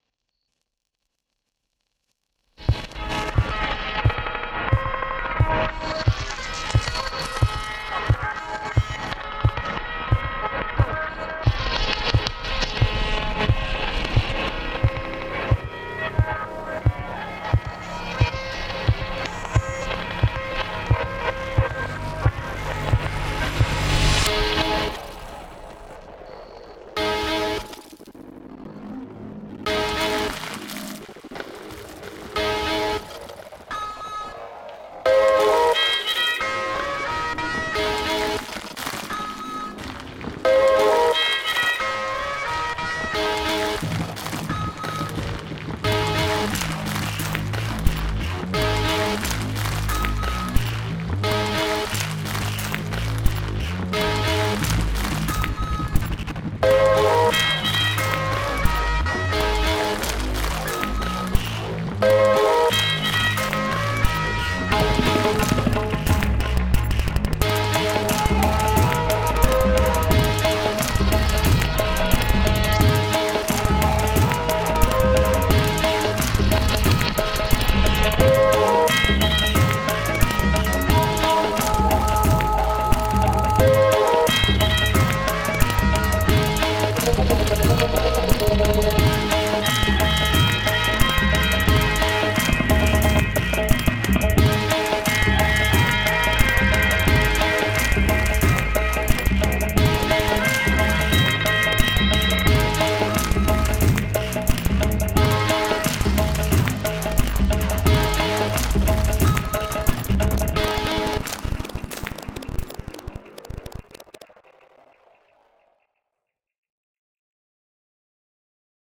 Genre : morceau instrumental
Instrument de musique : percussions
Danse : pantelon